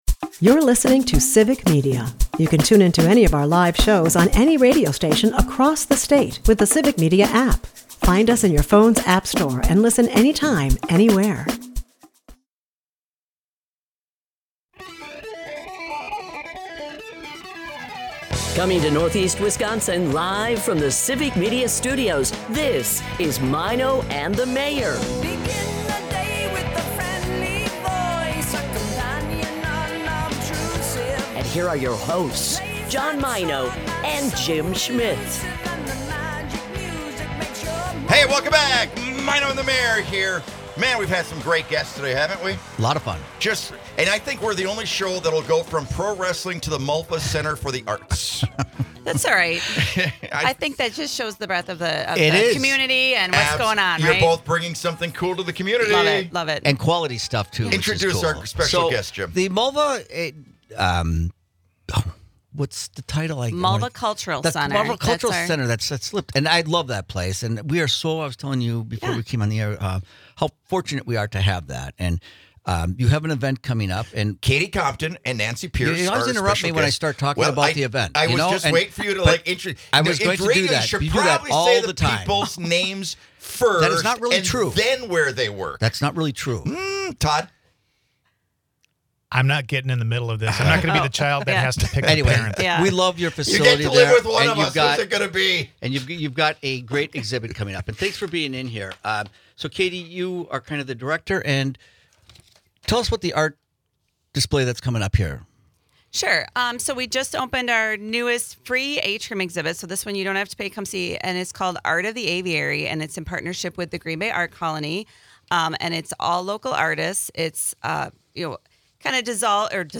The Green Bay Art Colony's century-long legacy as a women's art organization is celebrated, showcasing photography, sculptures, and mixed media on the theme of flight. Later, the studio buzzes with live music
who blend English and Spanish lyrics with original beats